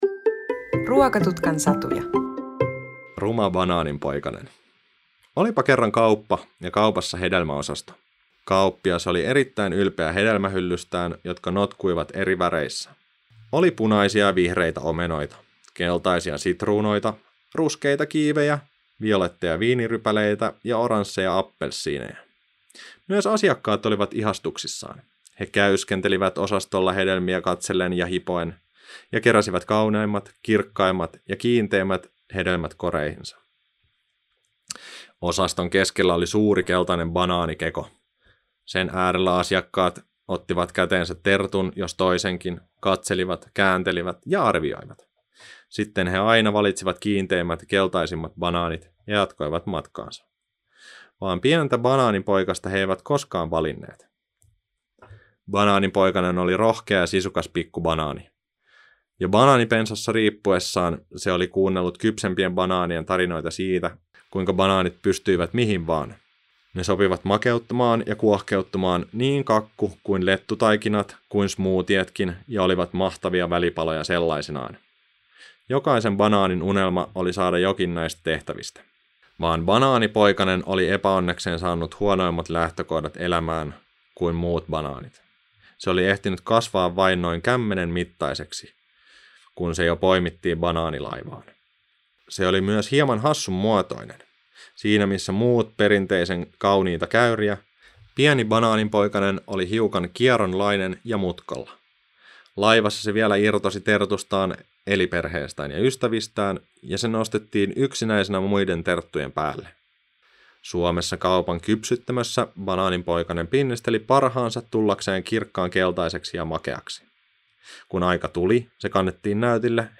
Ruma banaaninpoikanen -äänisatu